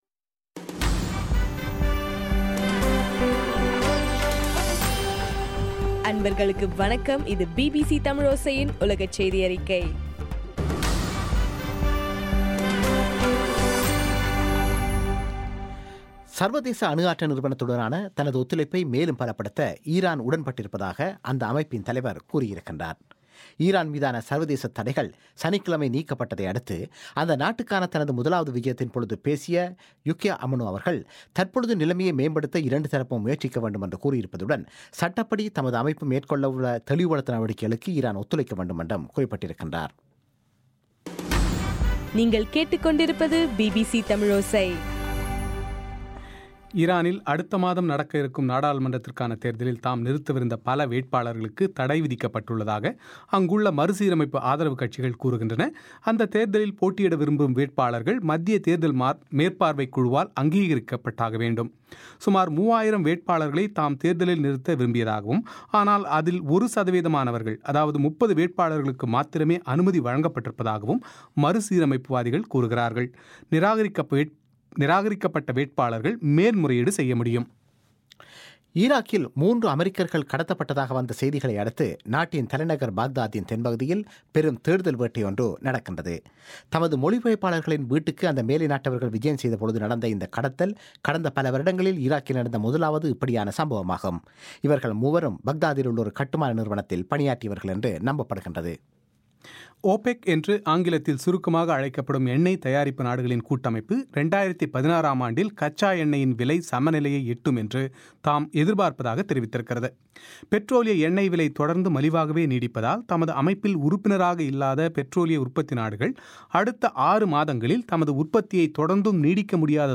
இன்றைய (ஜனவரி 18) பிபிசி தமிழோசை செய்தியறிக்கை